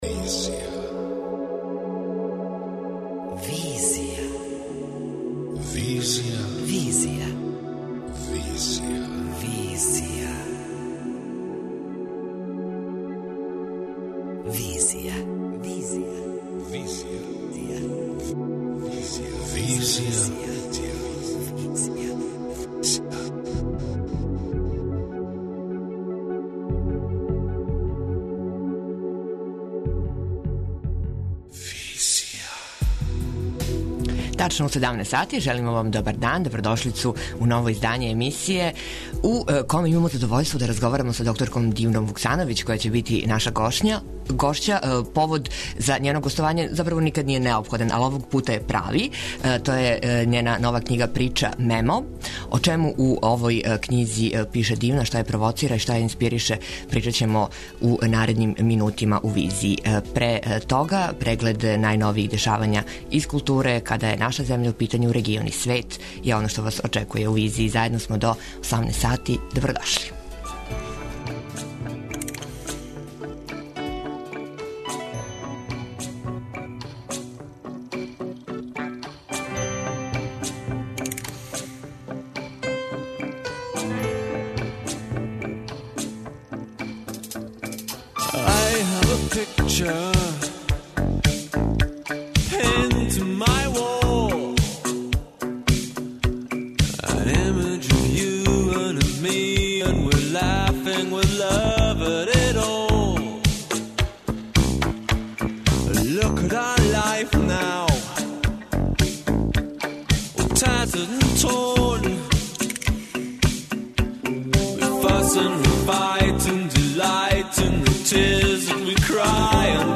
преузми : 26.84 MB Визија Autor: Београд 202 Социо-културолошки магазин, који прати савремене друштвене феномене.